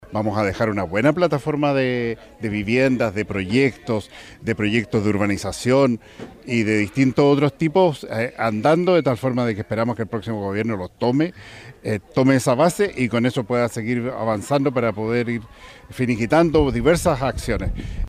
La iniciativa proyecta una tercera etapa de construcción, en la que se entregarán más soluciones habitacionales, proceso que, de acuerdo a lo señalado por el delegado presidencial, Yanino Riquelme, se espera tenga continuidad en la próxima administración.
casas-quilpue-delegado.mp3